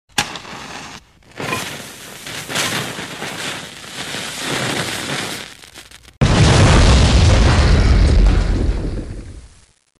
Подпал фитиля и грохот динамита